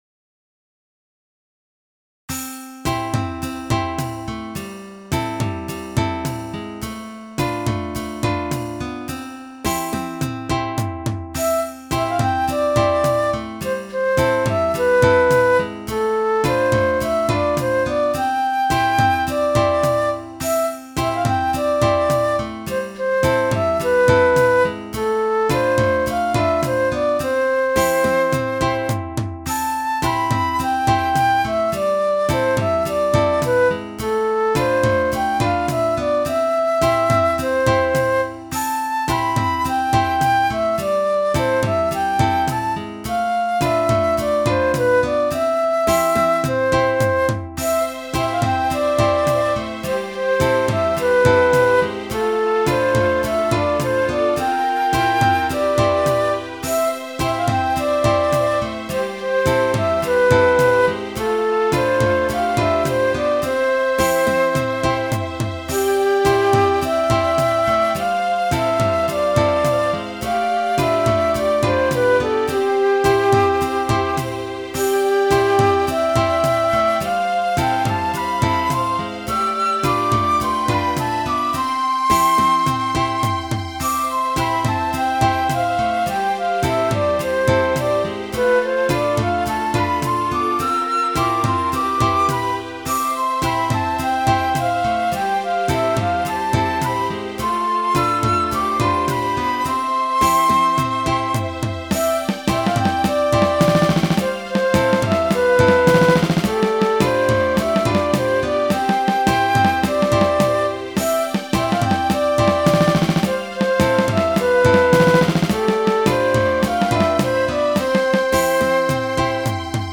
ジャンルPop